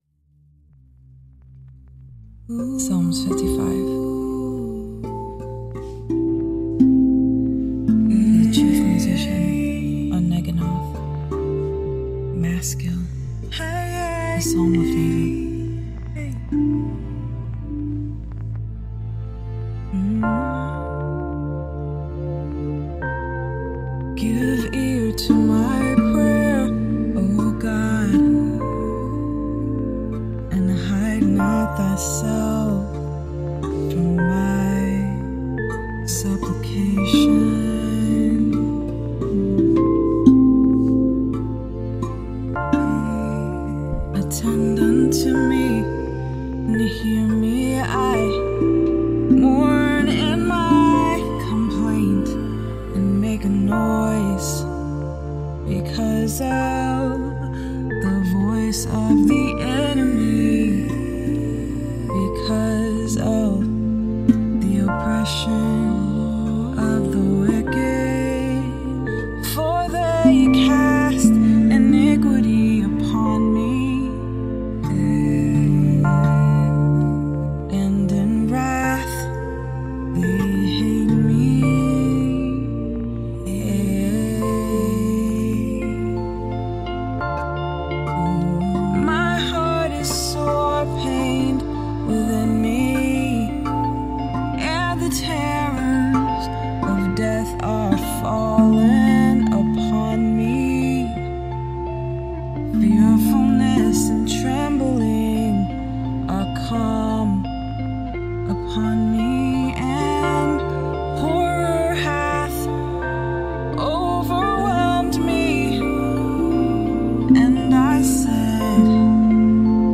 Rav vast drum